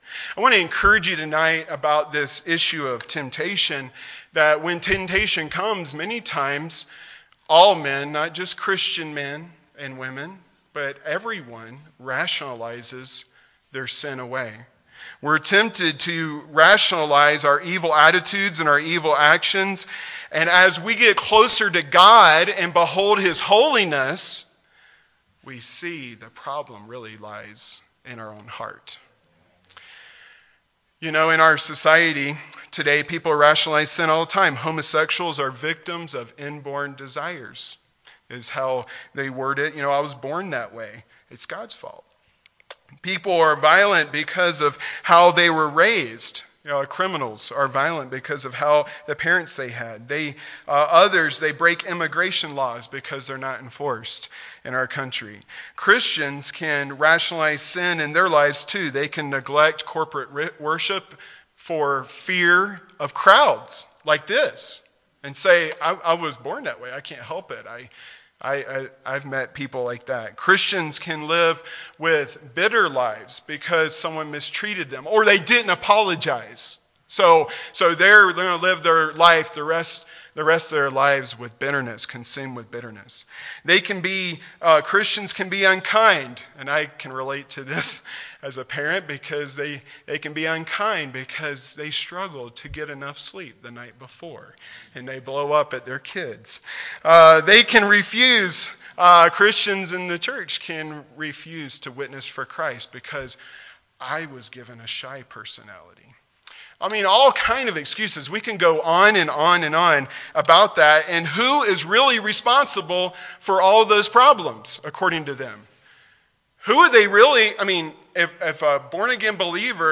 Genre: Sermons.